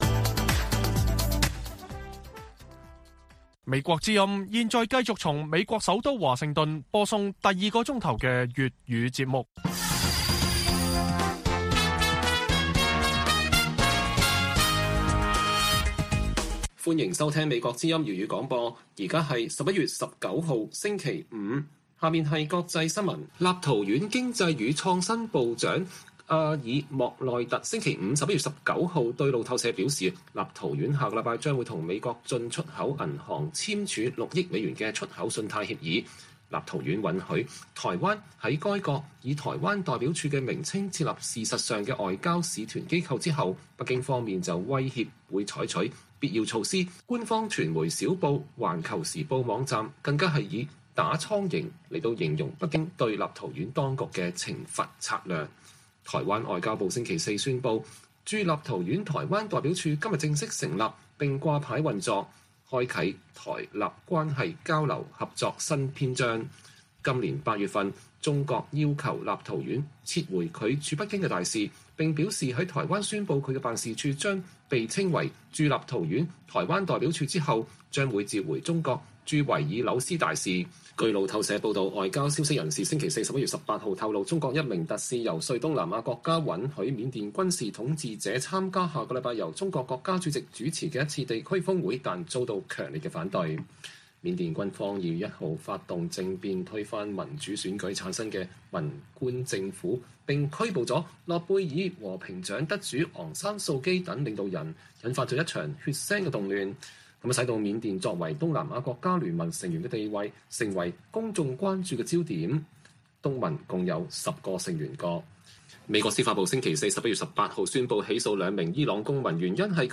粵語新聞 晚上10-11點：民調指近6成人認為香港選舉制度不公平